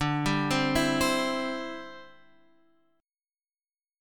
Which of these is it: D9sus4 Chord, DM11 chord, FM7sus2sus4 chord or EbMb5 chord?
D9sus4 Chord